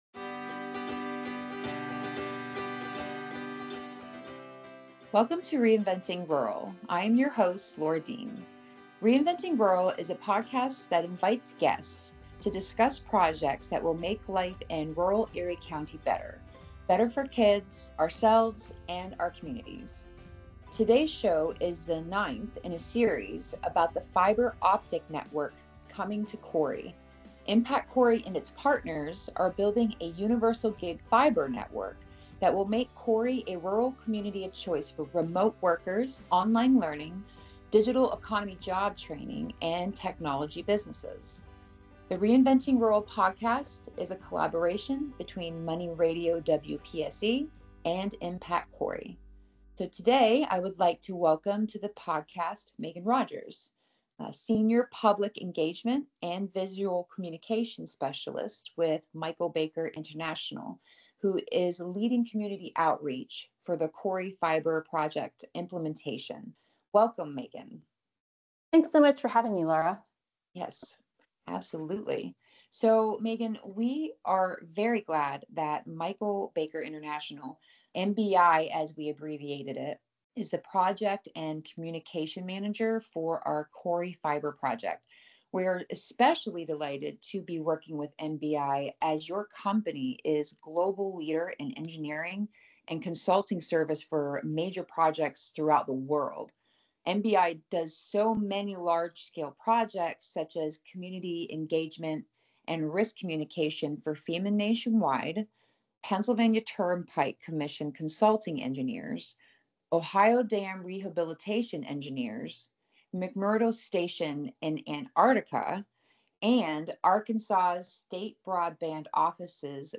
The Reinventing Rural: Connectivity – Episode 8 podcast explores the transformative potential of Corry's universal gig fiber optic network, highlighting its role in attracting remote workers, supporting online learning, and enabling a thriving digital economy. Host